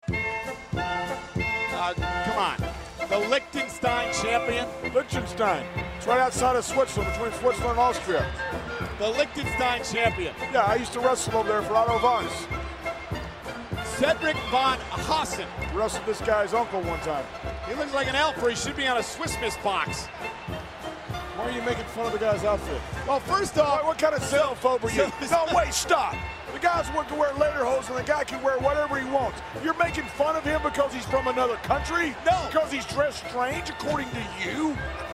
wacky Alpine music as JBL and Michael Cole bicker back and forth in a manner most annoying.